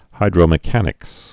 (hīdrō-mĭ-kănĭks)